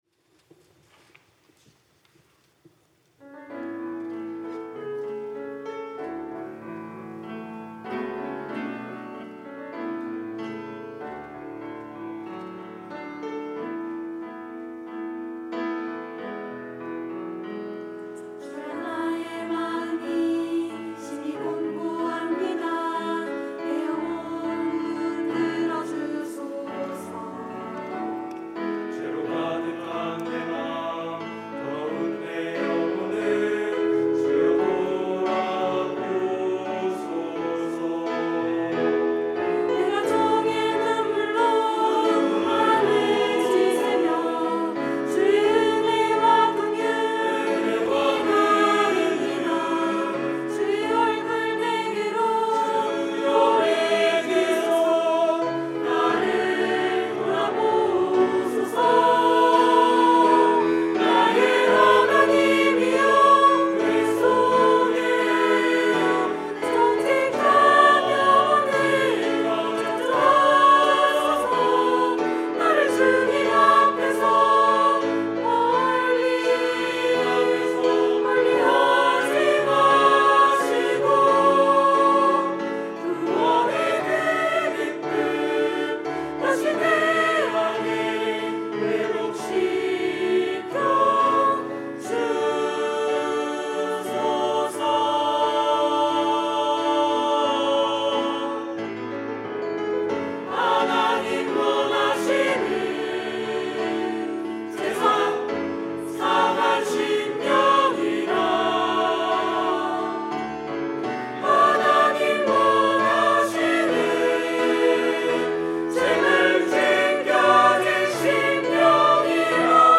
특송과 특주 - 회복
청년부 카이노스 찬양대